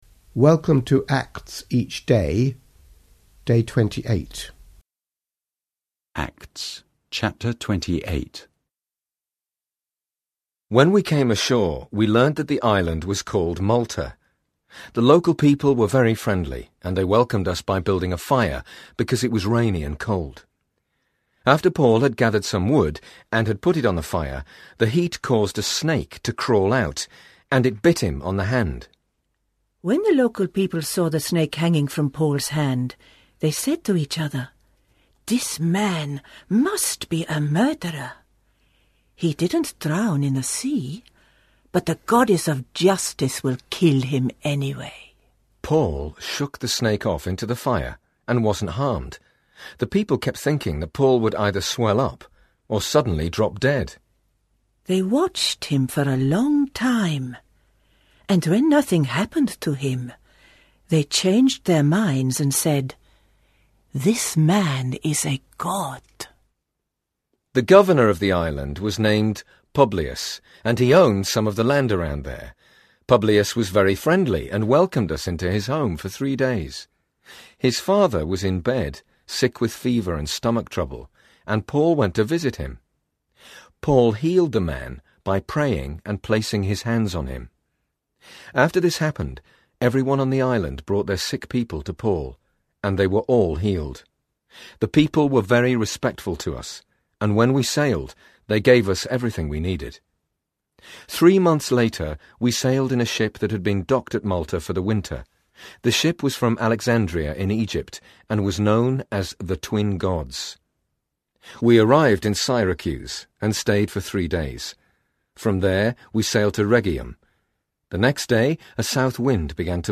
A daily reading from the Acts of the Apostles for July 2012